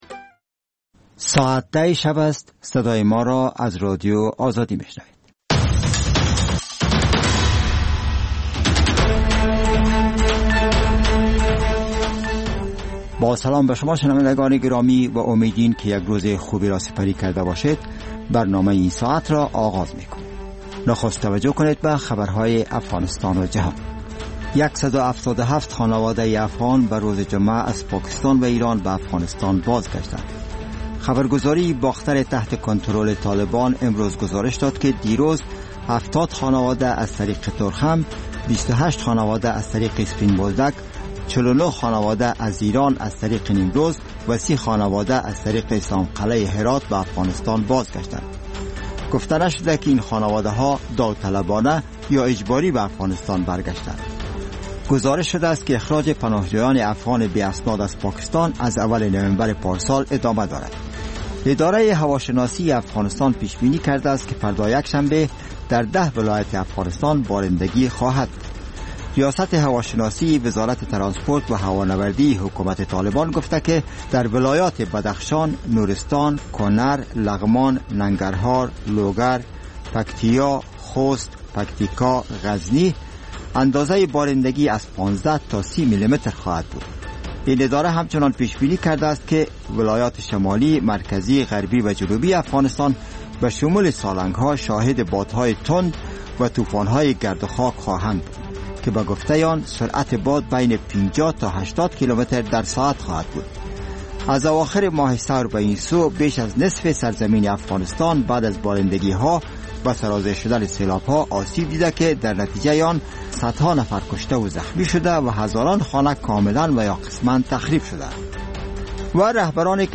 ساعت خبری شبانه